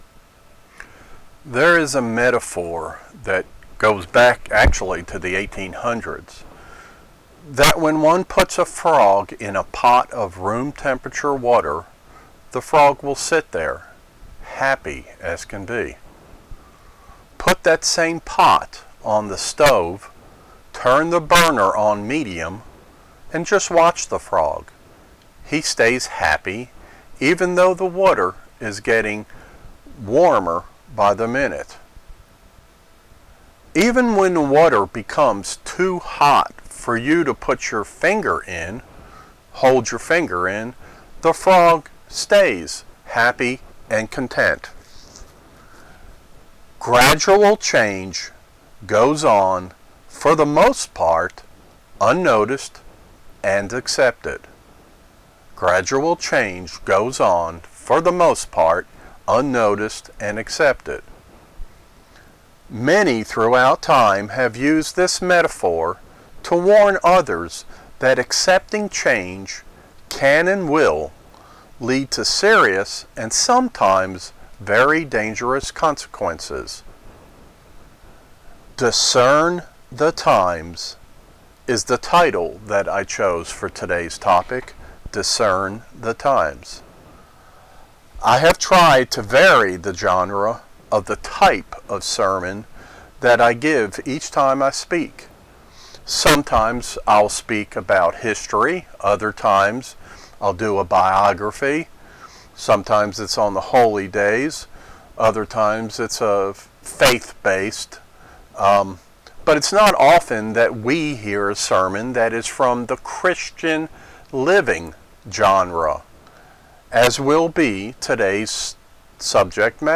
Sermons
Given in Kingsport, TN